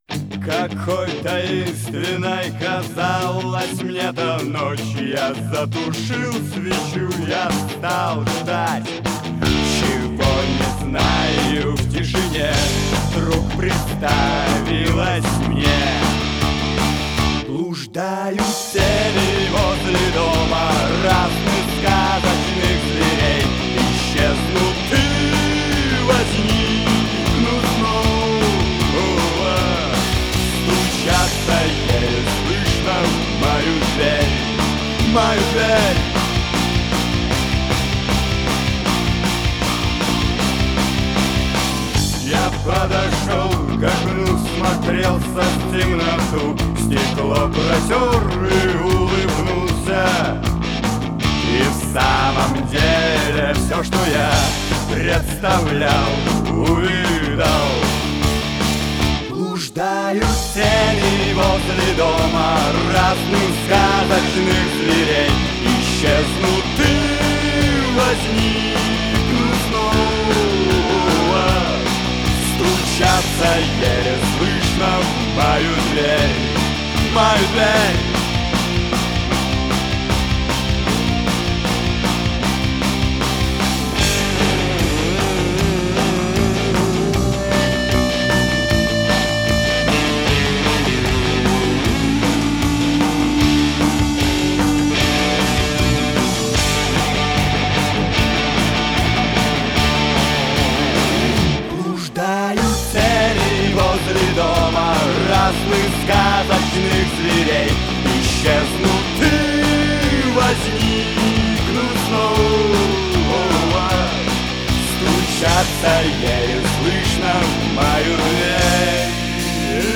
Жанр: Punk Rock